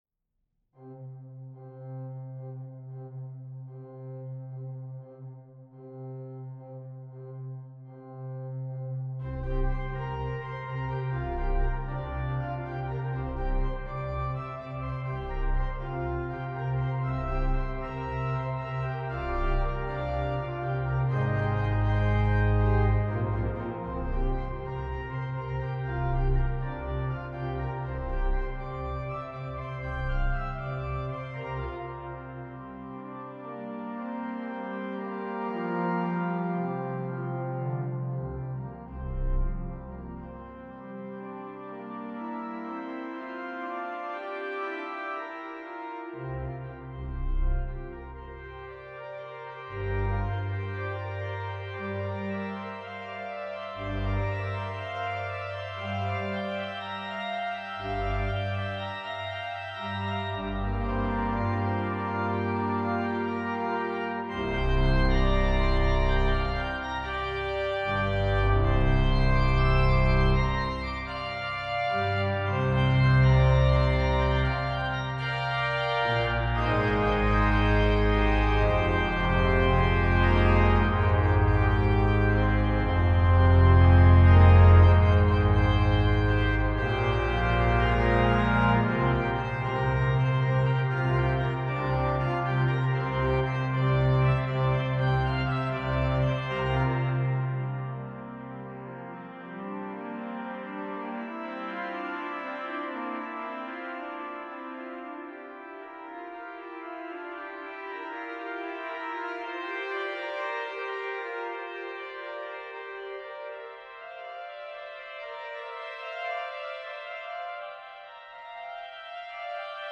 for Organ (2024)
organ.